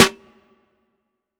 SHGZ_SNR.wav